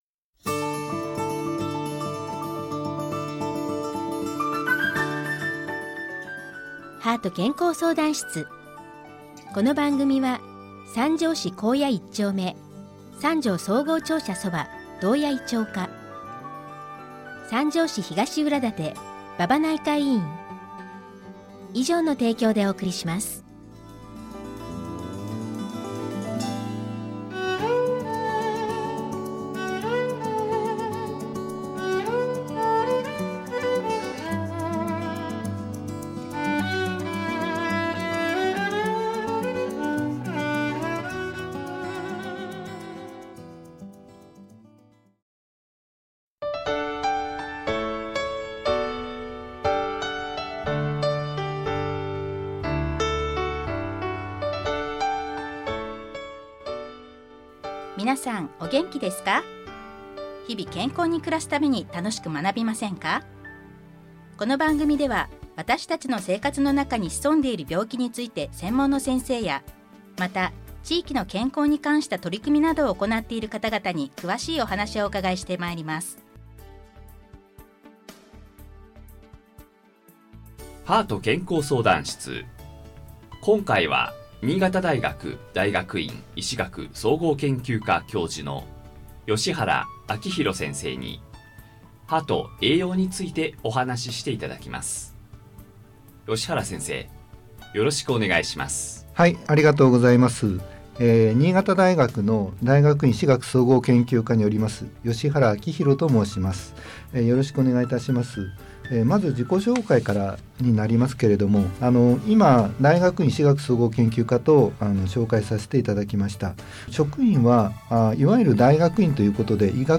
「R6年7月インタビュー」